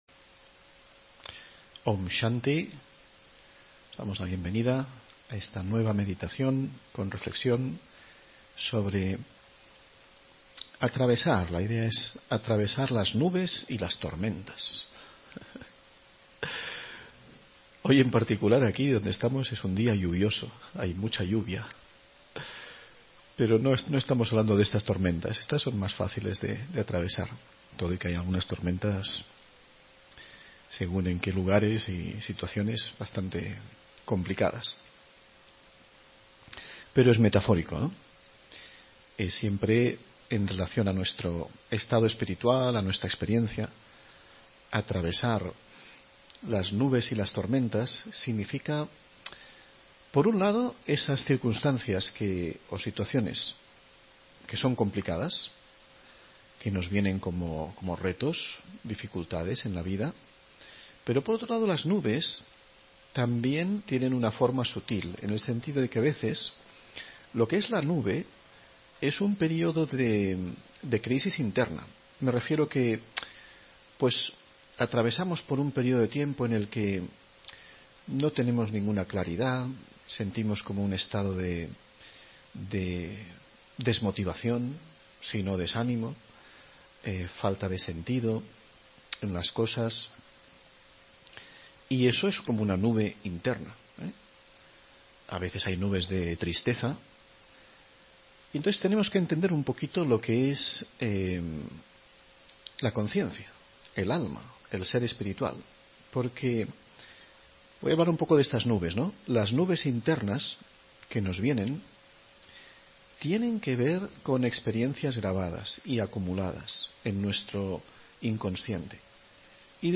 Meditación y conferencia: Superar las nubes y las tormentas (26 Marzo 2024)